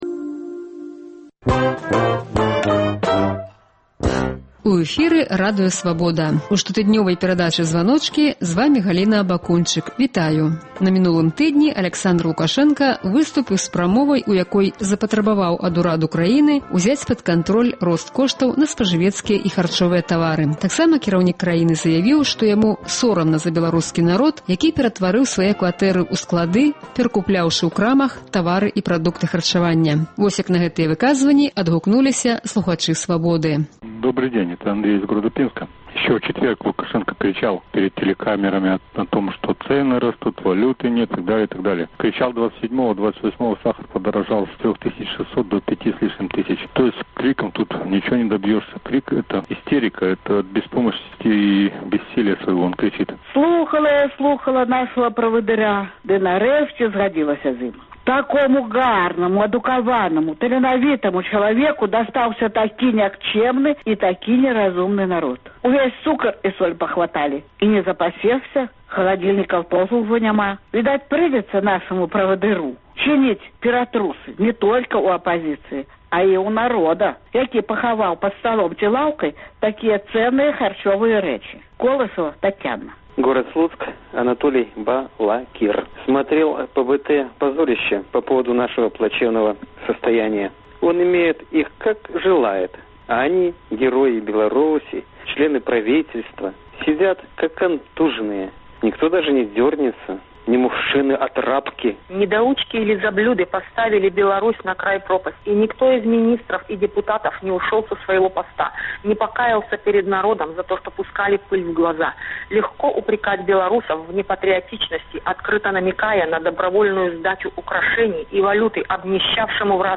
Агляд званкоў на Свабоду